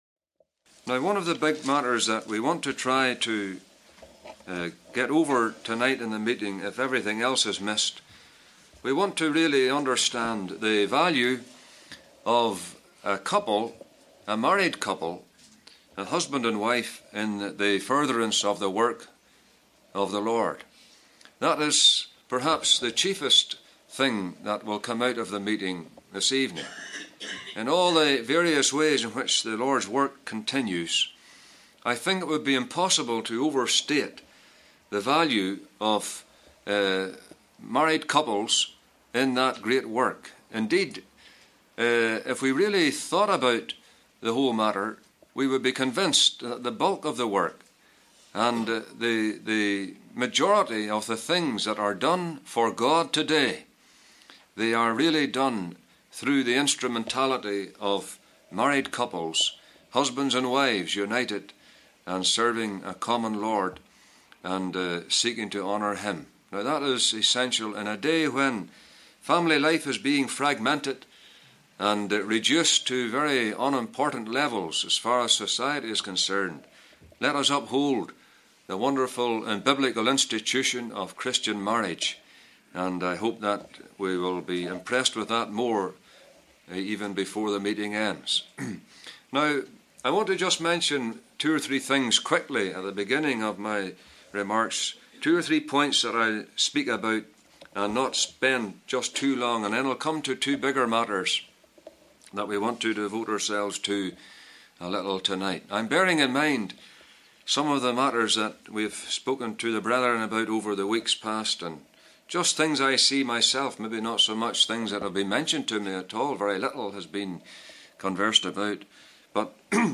Their mentions, merchandise, movements, marriage and ministry. (Recorded in Ahoghill Gospel Hall, Northern Ireland).